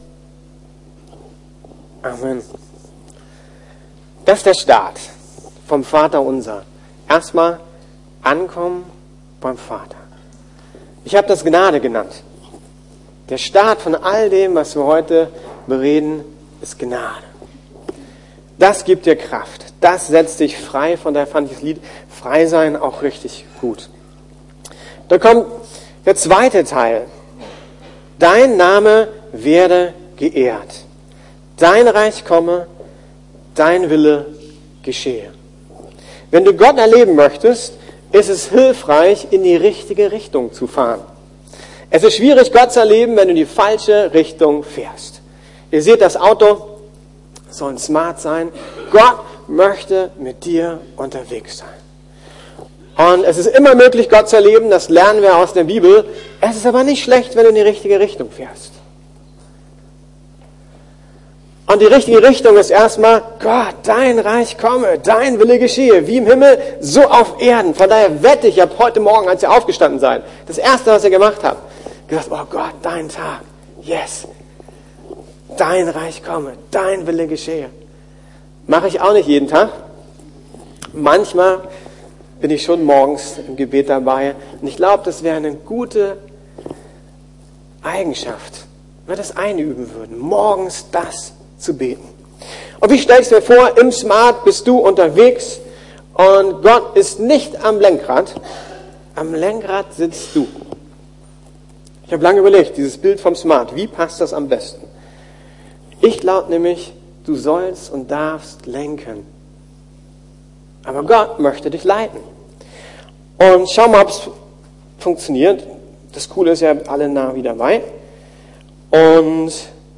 Gnade: gemeinsam wachsen, gemeinsam Gott erleben ~ Predigten der LUKAS GEMEINDE Podcast